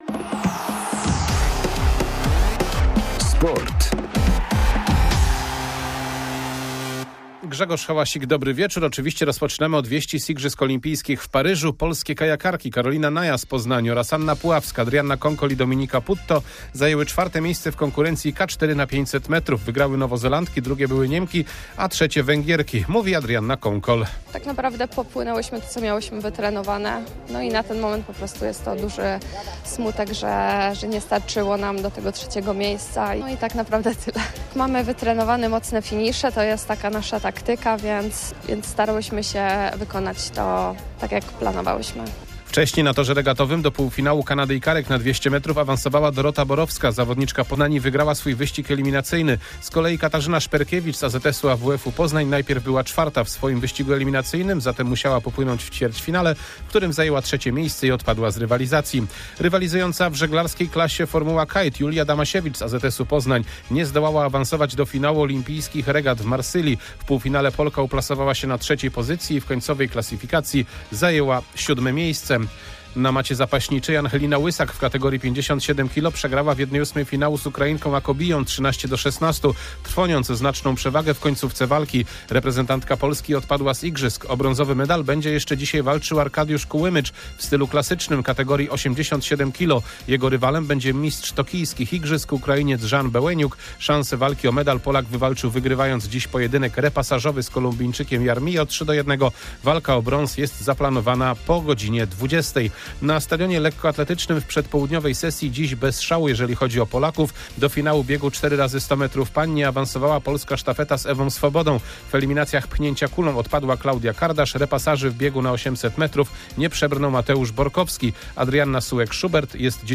08.08.2024 SERWIS SPORTOWY GODZ. 19:05